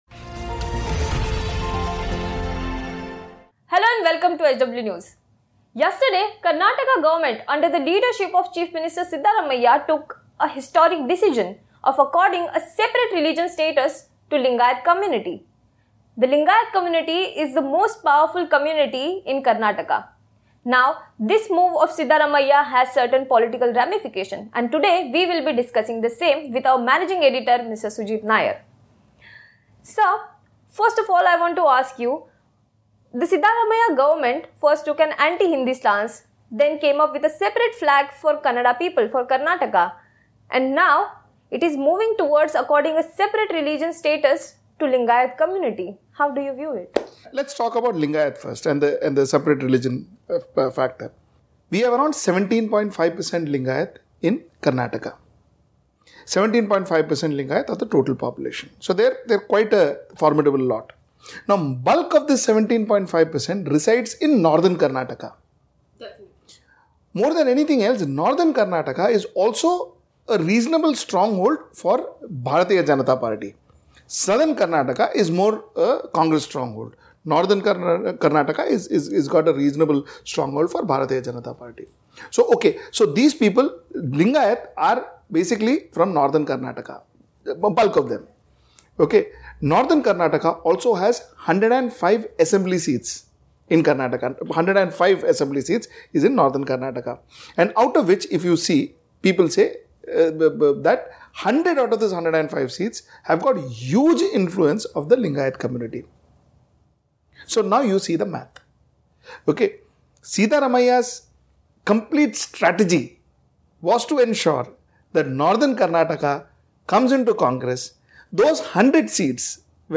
News Report / Lingayat Separate religion status | Karnataka Assembly Election2018